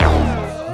TGOD Bossed Up Pluck.wav